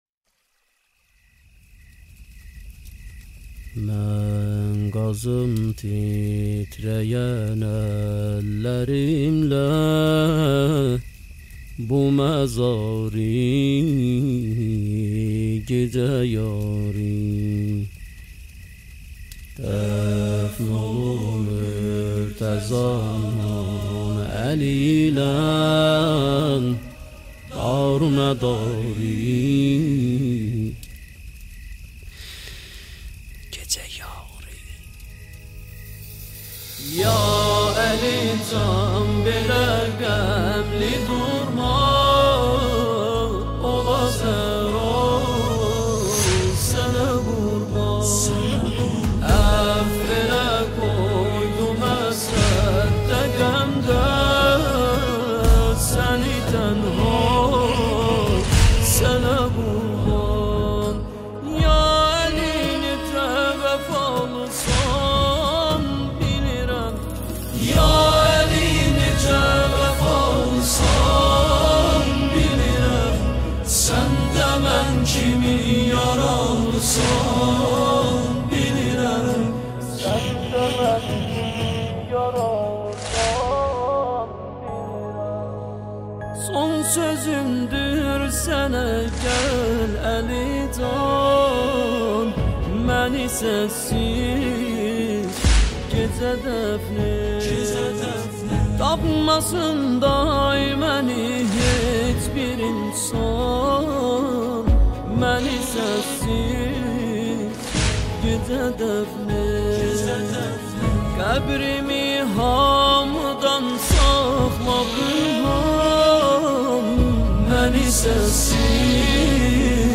دانلود مداحی ترکی «گجه دفن ات» با نوای دلنشین